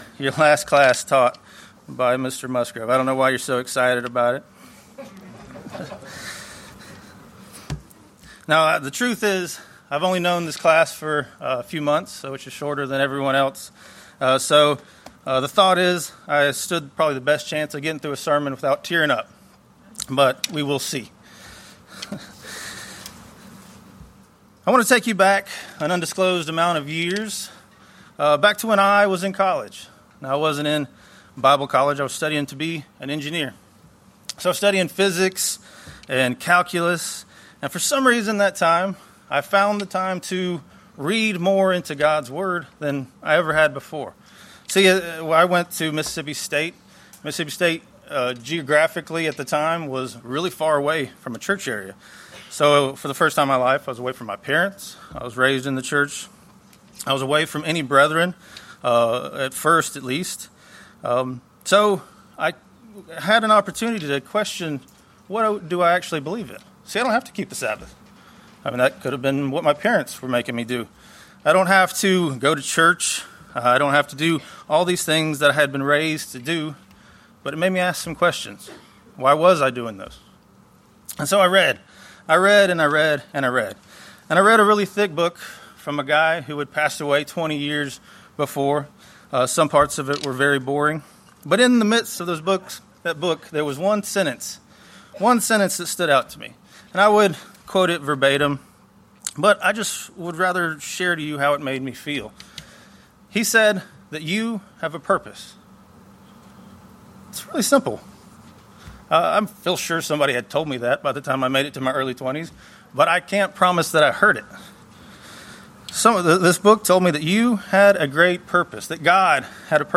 This sermon, rooted in Proverbs 16:3 and the Nazarite vow from Numbers 6, challenges graduates—and all believers—to commit their lives to God by embracing a set-apart, holy calling. Using the vow’s conditions as spiritual metaphors, it emphasizes living differently from the world, sacrificing self, and staying focused on the living God.